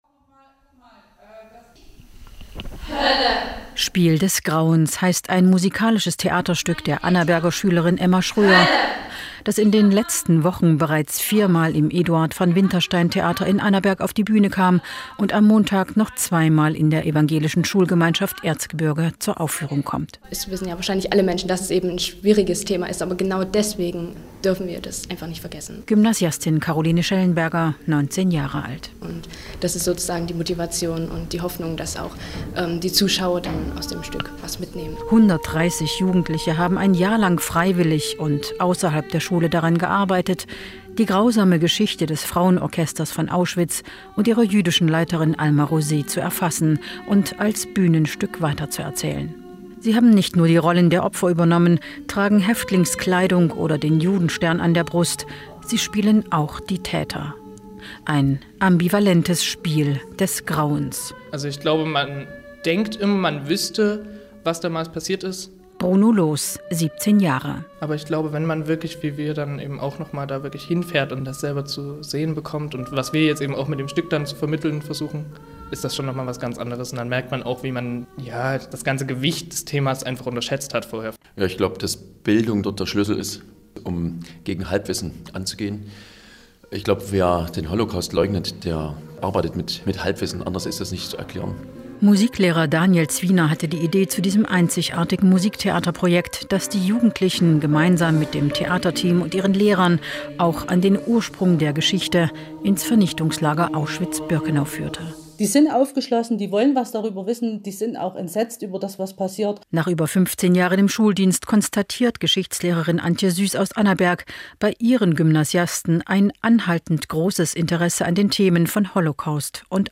Hier noch 2 Radiobeiträge